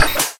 laser-beam-end-2.ogg